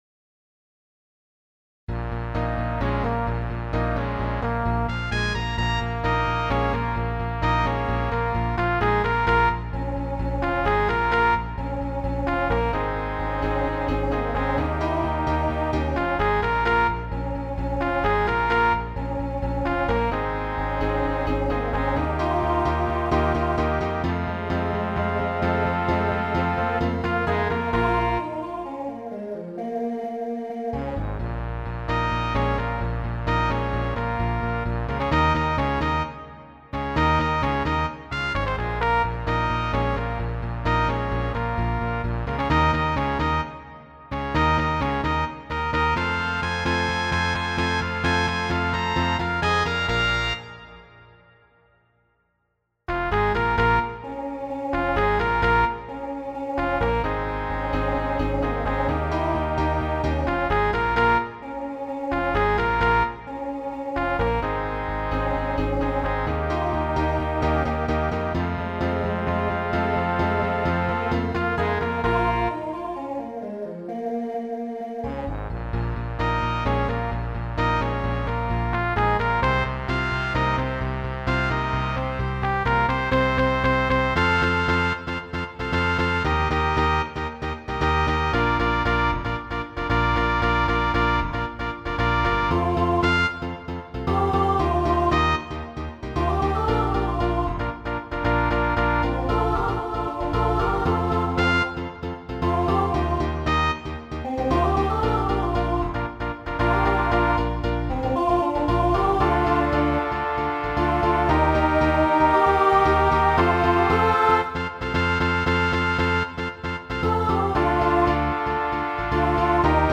TTB/SSA
Voicing Mixed
Pop/Dance , Rock